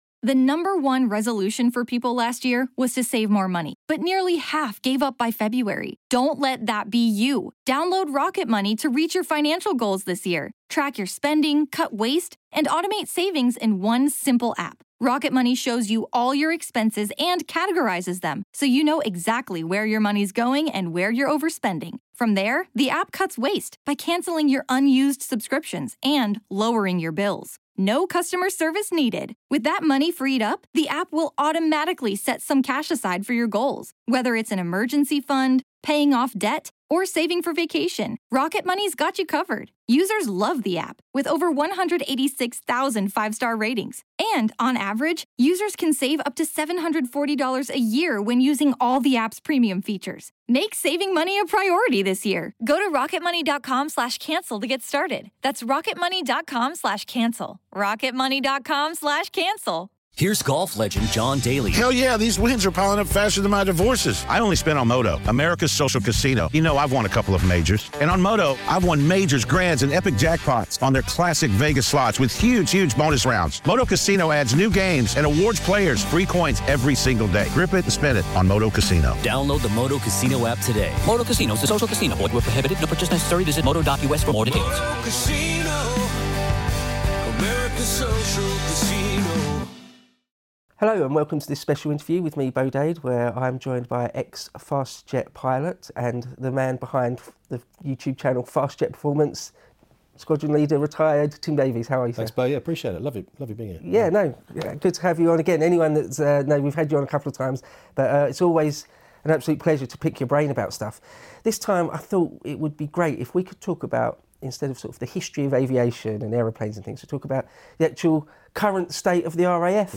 Problems with the RAF | Interview